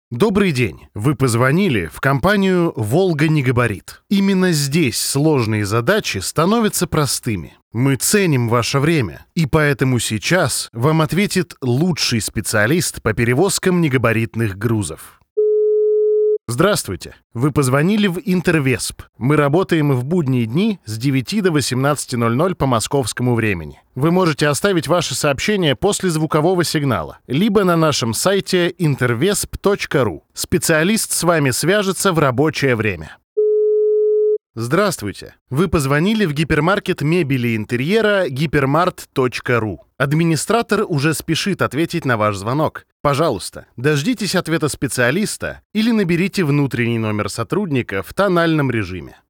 Муж, Автоответчик/Средний
Живой, естественный голос с широким спектром возможностей для разных задач.
Акустически подготовленное помещение с качественным оборудованием.